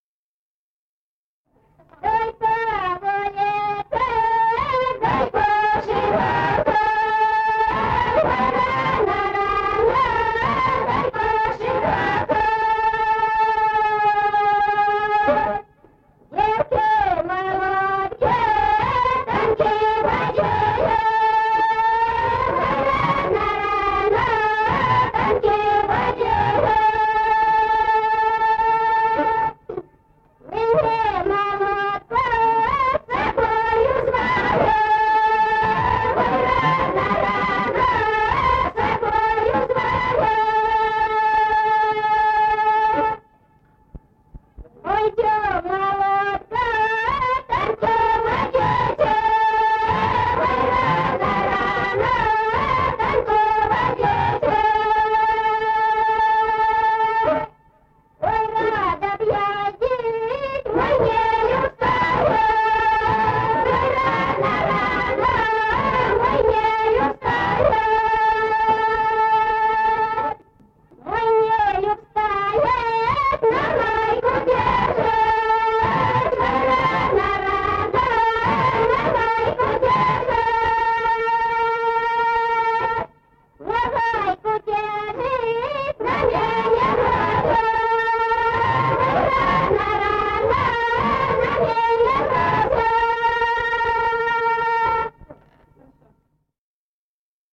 Народные песни Стародубского района «Чтой по улице», духовская таночная.
с. Курковичи.